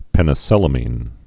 (pĕnĭ-sĭlə-mēn)